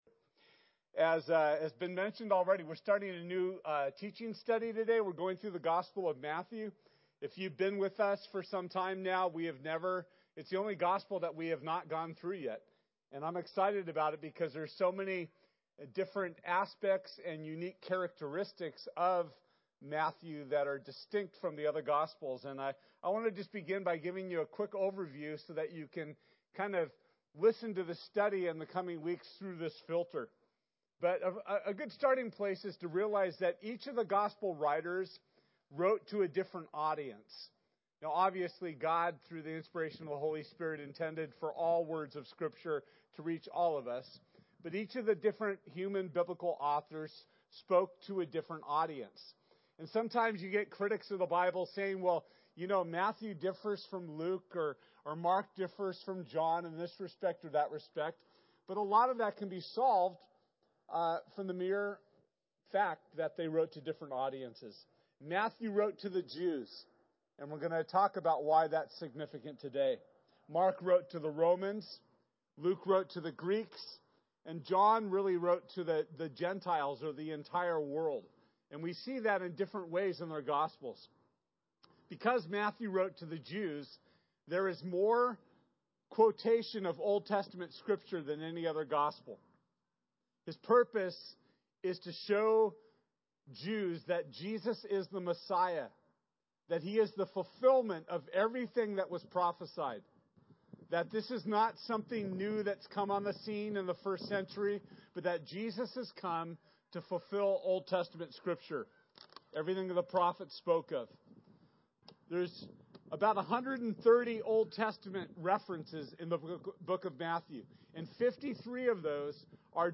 Matthew 1:1-17 Service Type: Sunday This week we’ll be launching our new teaching series.